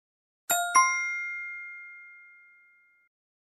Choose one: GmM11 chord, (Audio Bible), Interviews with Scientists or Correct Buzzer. Correct Buzzer